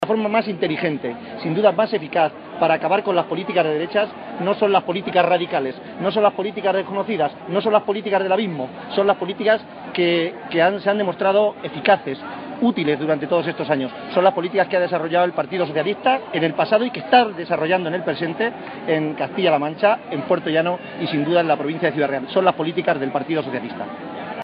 Acto público en Puertollano
Cortes de audio de la rueda de prensa